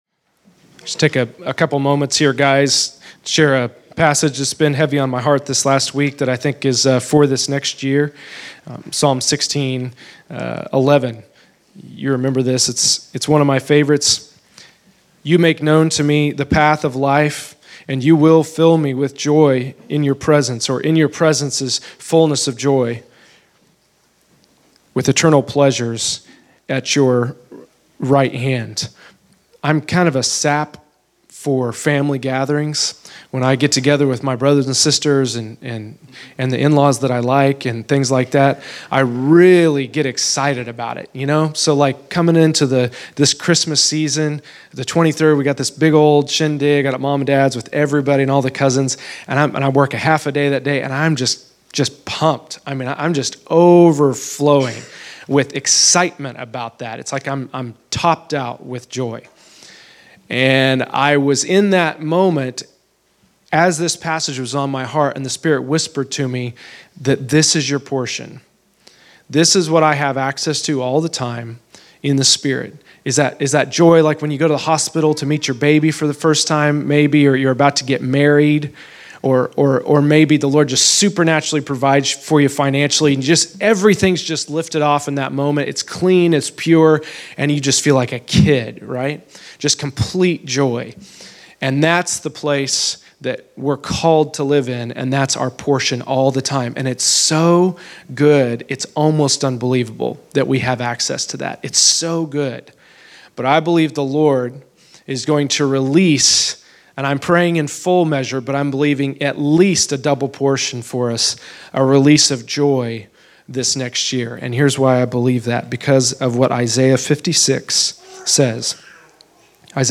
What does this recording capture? Category: Scripture Teachings